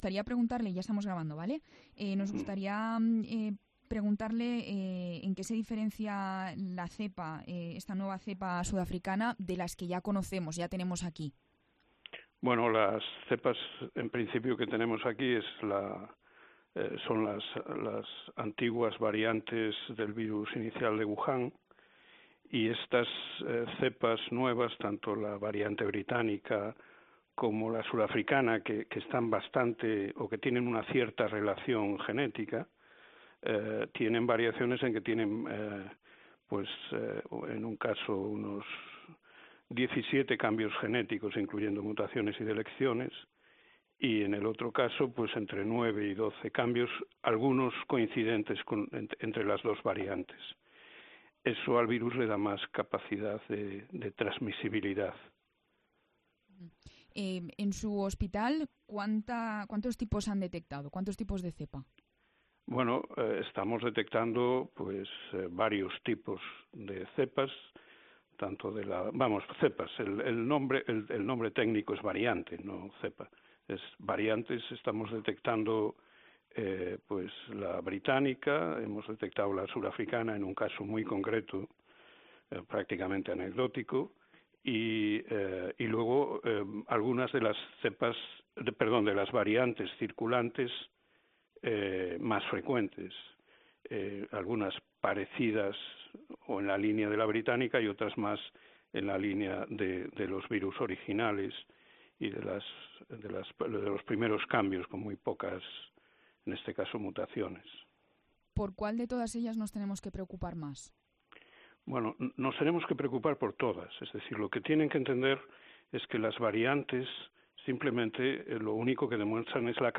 microbiólogo